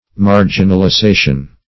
marginalisation - definition of marginalisation - synonyms, pronunciation, spelling from Free Dictionary